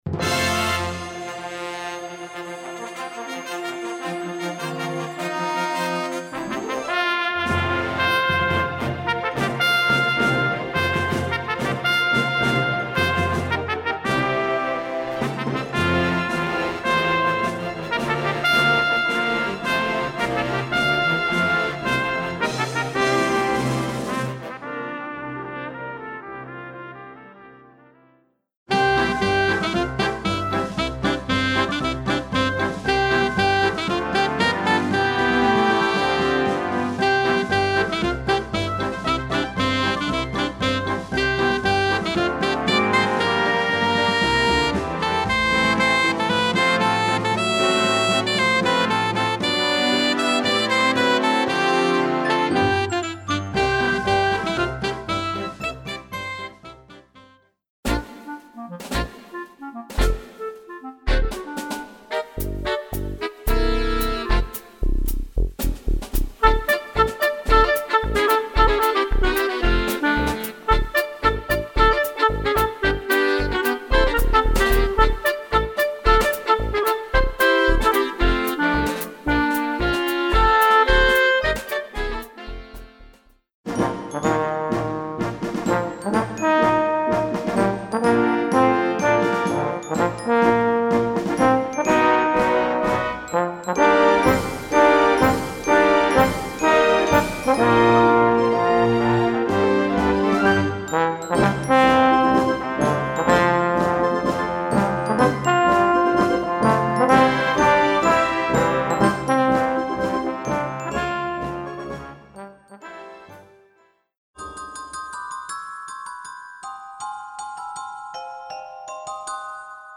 Voicing: Piano Accompaniment